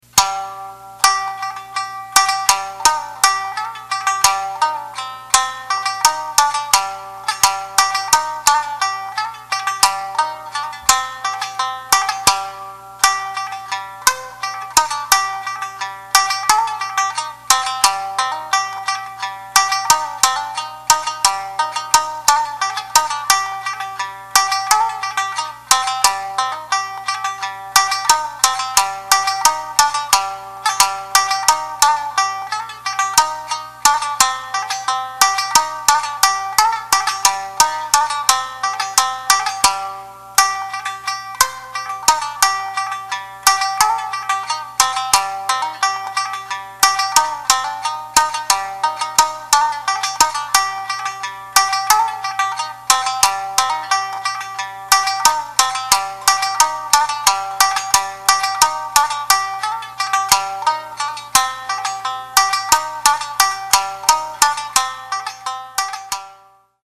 究極の癒し、サンシンの響きをお楽しみください
※このCDは「インストゥルメンタル」で唄は収録されていません
三線
人の声が入っていないという事は、時になぜかほっとさせる。